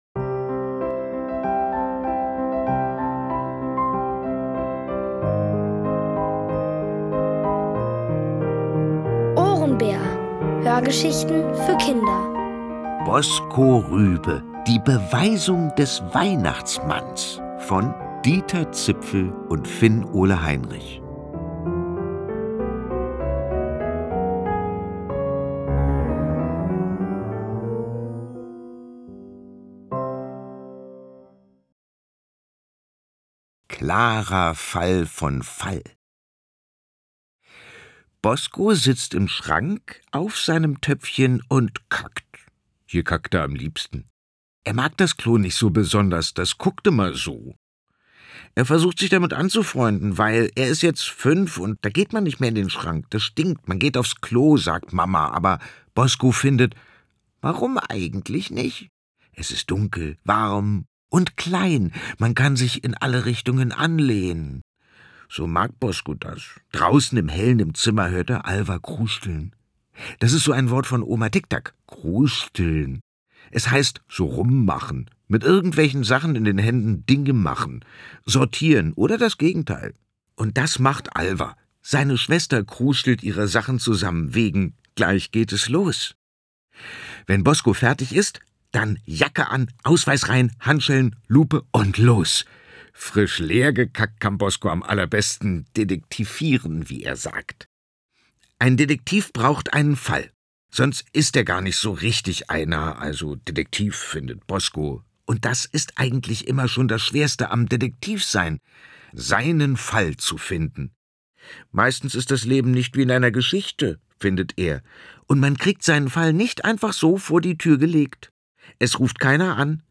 Erzählt von Boris Aljinovic.